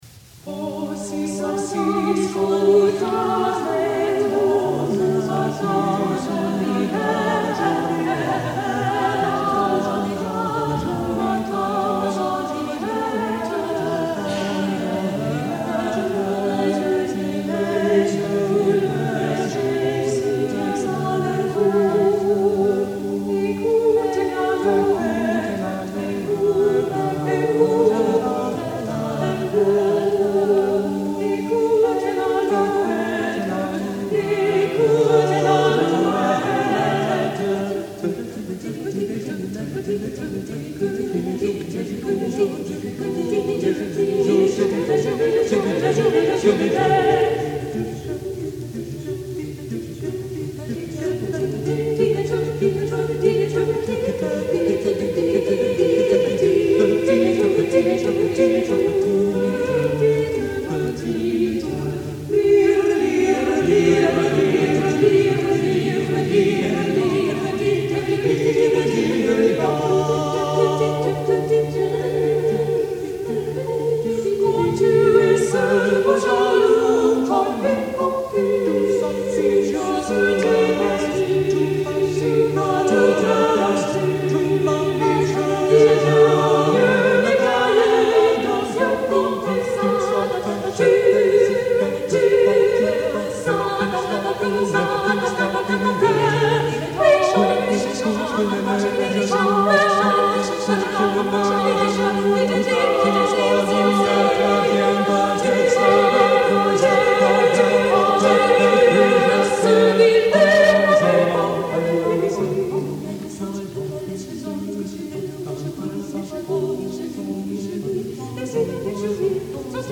| Vocal ensemble 'All Creatures' 1976